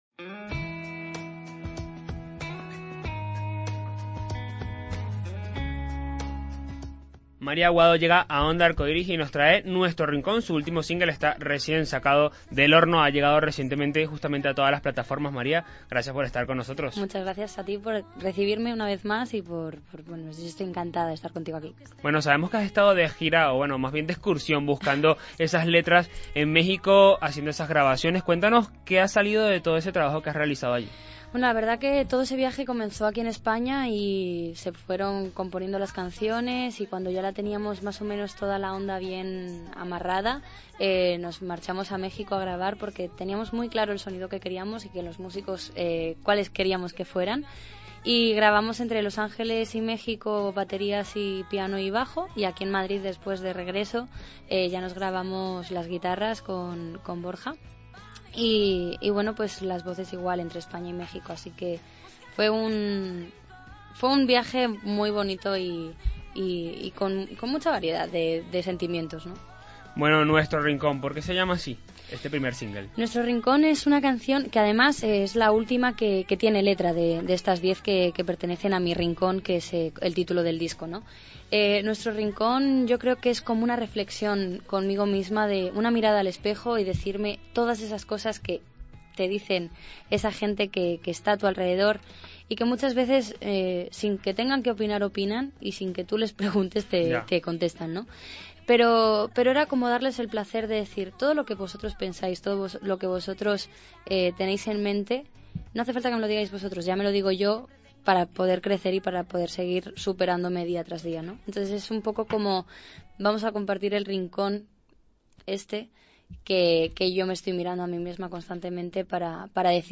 Antes de todo esto, pasó por lo micrófonos de ondaarcoiris para presentárnoslo y para darnos los detalles de su nuevo trabajo.